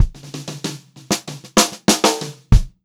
96POPFILL2-R.wav